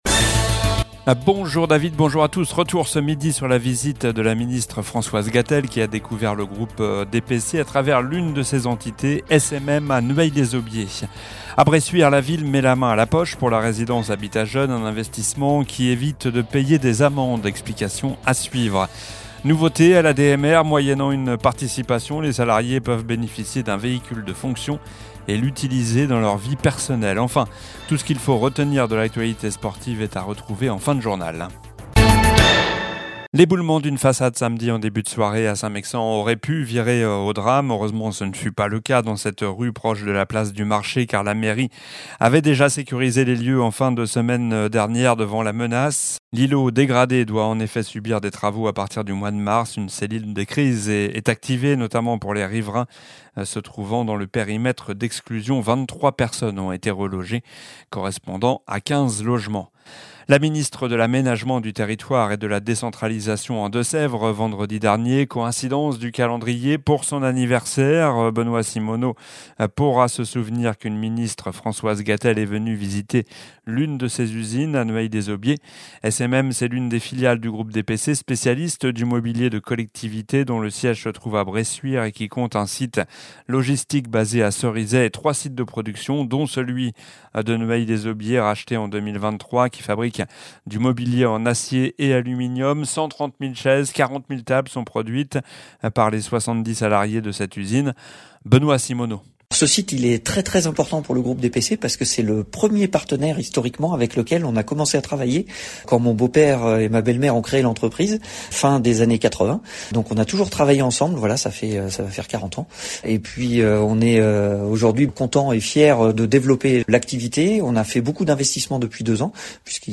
Journal du lundi 9 février (midi)